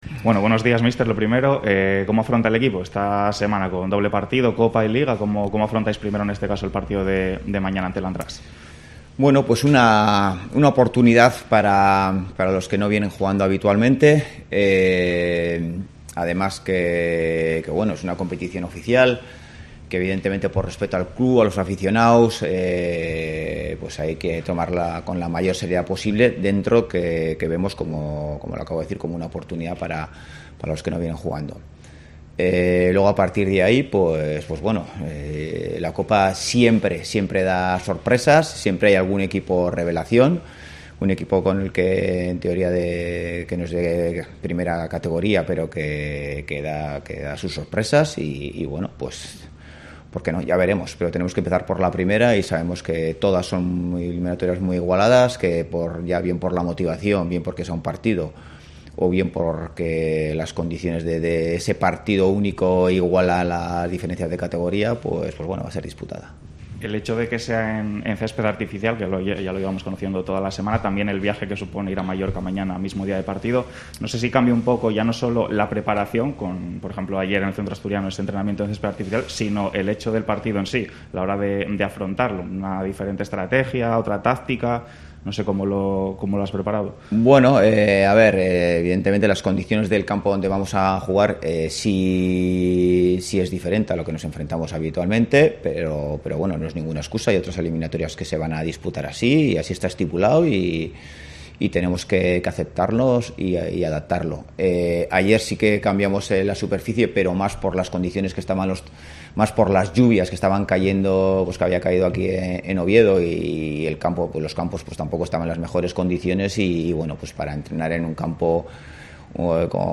Rueda de prensa Ziganda (Andratx-Oviedo)